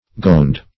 Gowned \Gowned\ (gound), p. a.